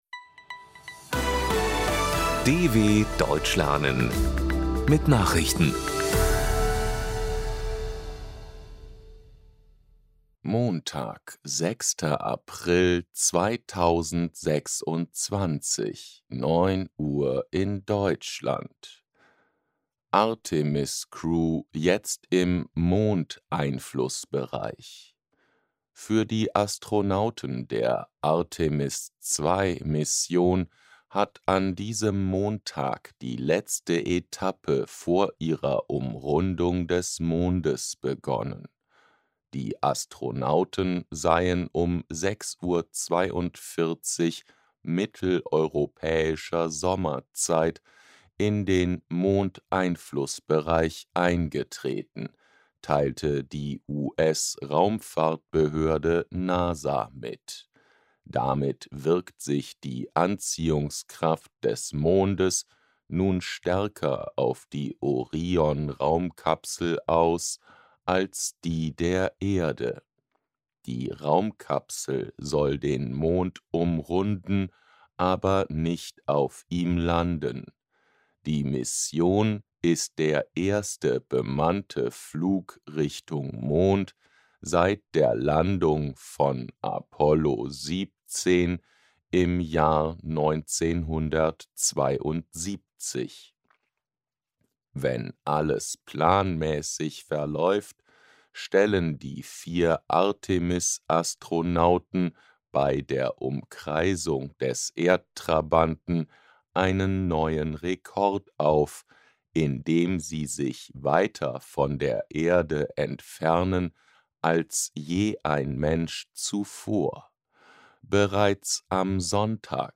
06.04.2026 – Langsam Gesprochene Nachrichten – Trainiere dein Hörverstehen mit den Nachrichten der DW von Montag – als Text und als verständlich gesprochene Audio-Datei.